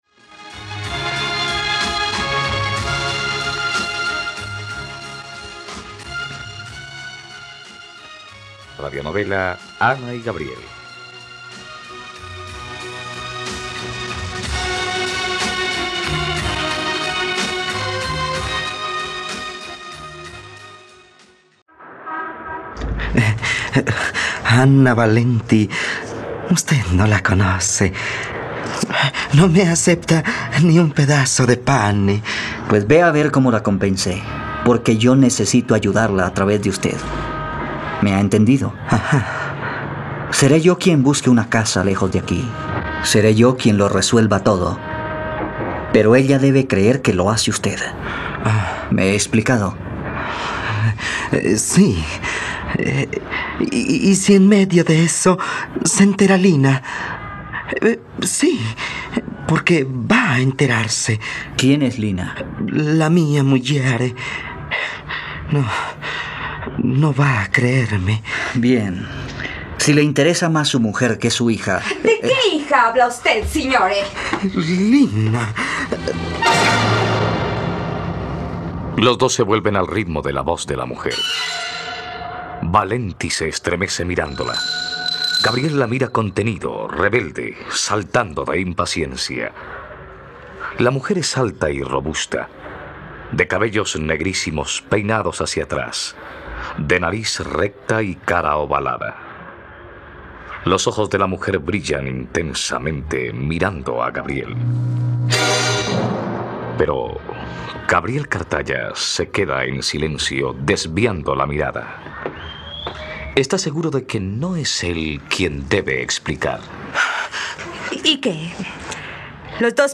..Radionovela. Escucha ahora el capítulo 51 de la historia de amor de Ana y Gabriel en la plataforma de streaming de los colombianos: RTVCPlay.